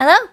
Worms speechbanks
Hello.wav